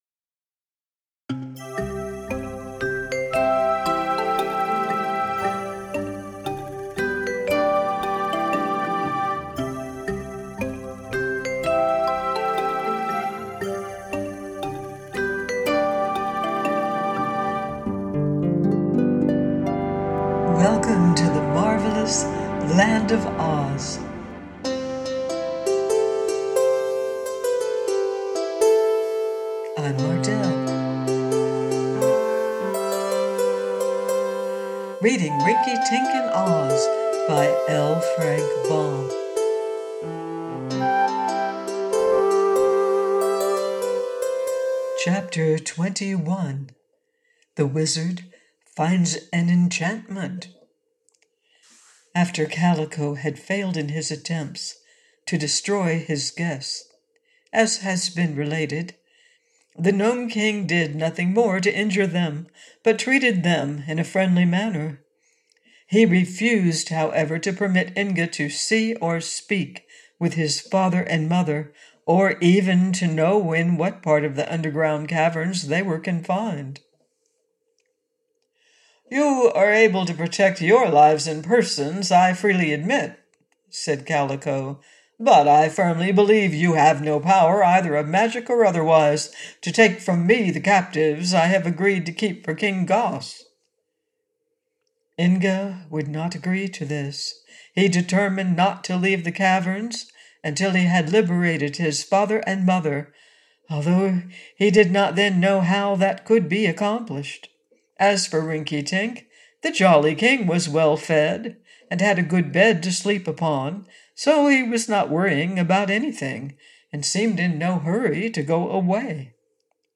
Rinkitink In Oz – by Frank L. Baum - AUDIOBOOK